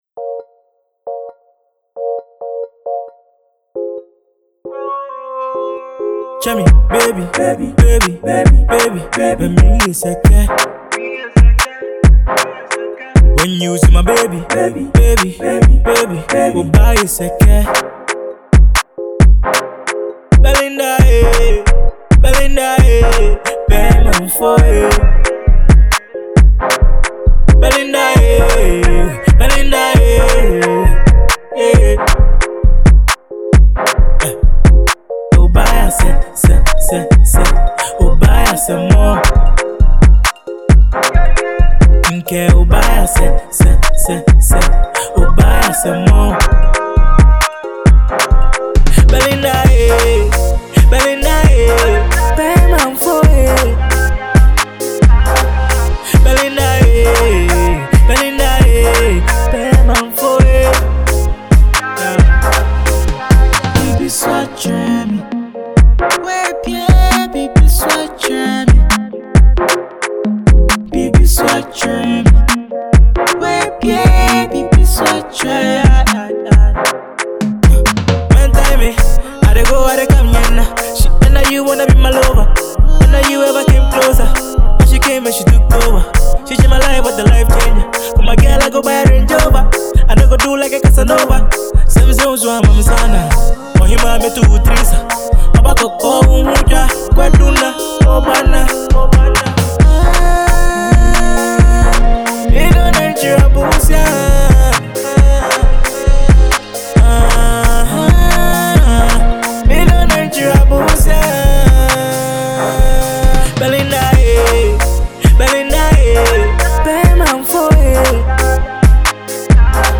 is a fast tempo and very danceable song